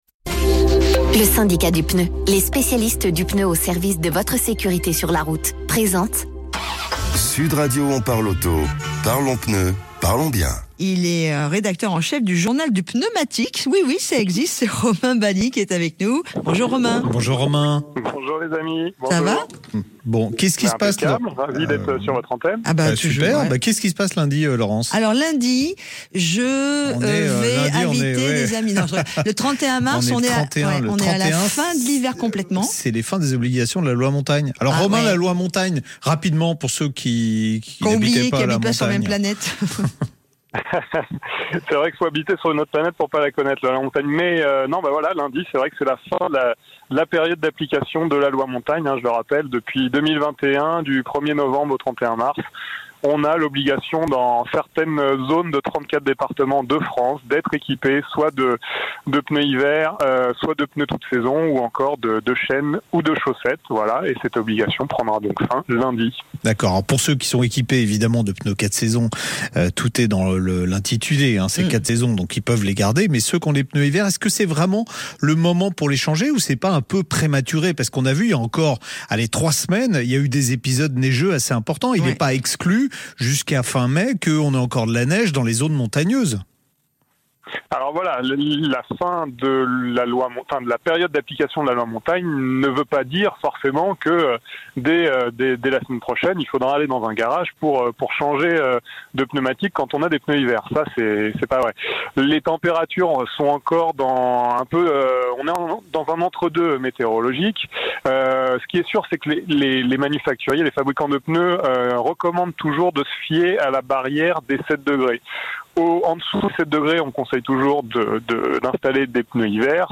Loi Montagne : le JDP intervient sur Sud Radio
Le Journal du Pneumatique a pris part à l'émission en y apportant son éclairage.